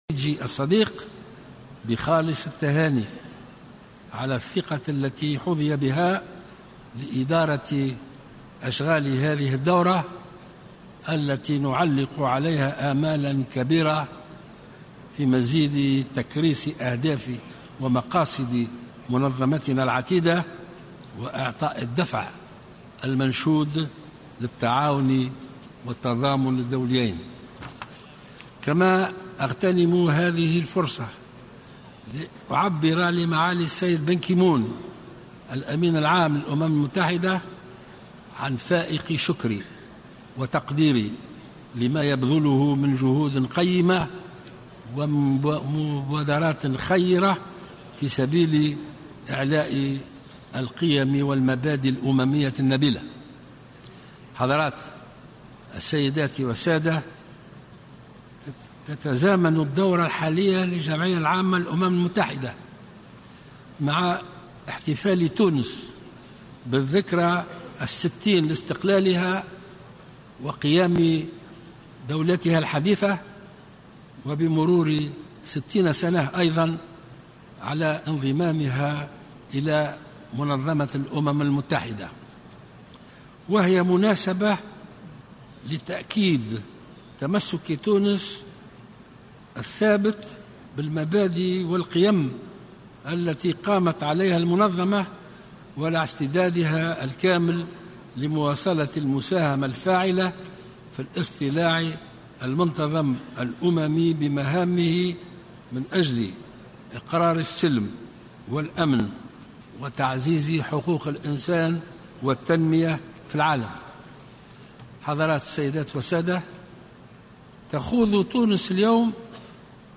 واضاف في كلمة القاها اليوم الثلاثاء امام الجمعية العامة للامم المتحدة في دورتها الـ71 ان 'تونس ما تزال، بعد خمس سنوات من الثورة، تجابه تحديات هائلة في سبيل تحقيق شروط الانتعاش الاقتصادي وتحسين ظروف العيش'.